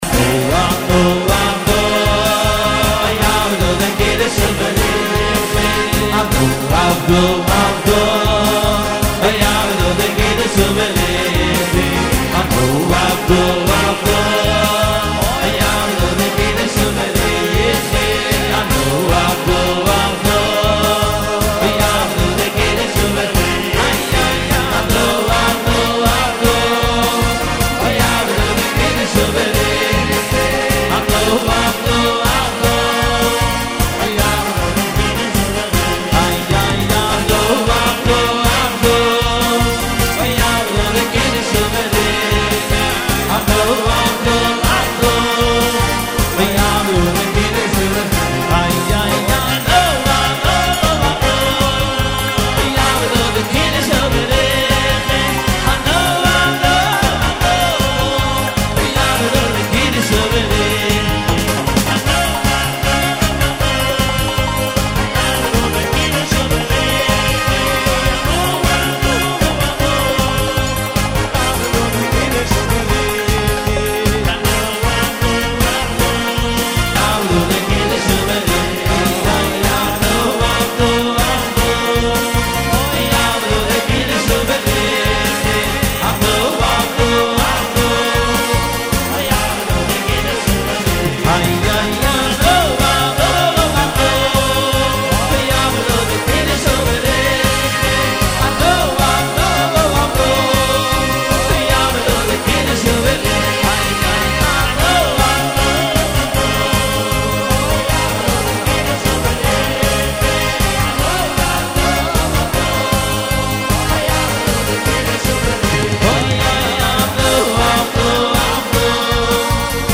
מקצב - אשכול מקצבים